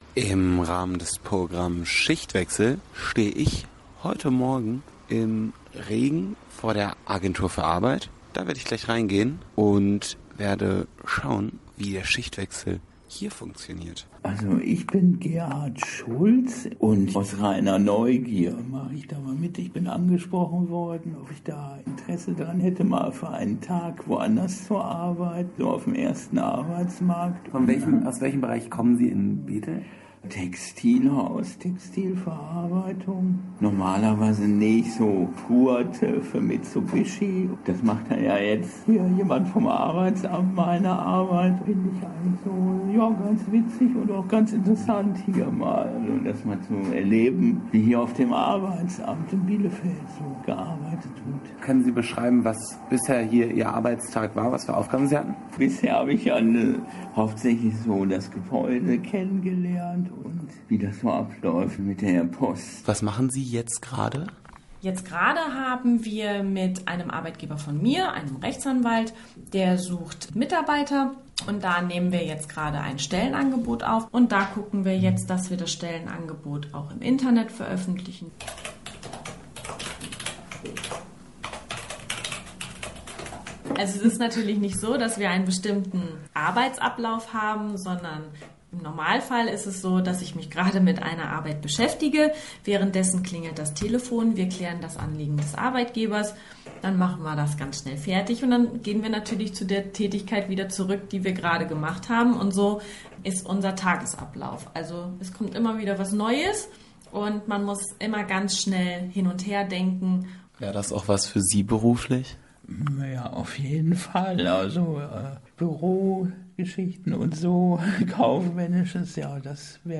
Podcast-Reportage-Schichtwechsel-Arbeitsamt.mp3